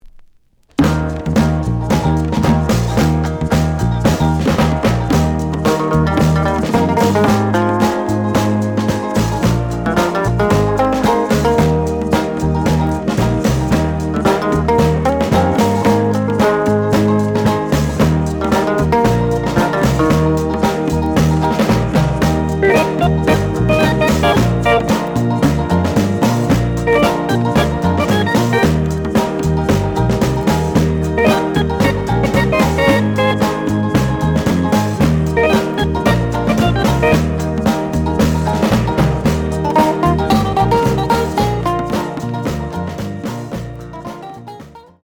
試聴は実際のレコードから録音しています。
The audio sample is recorded from the actual item.
●Genre: Funk, 60's Funk